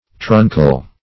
\Trun"cal\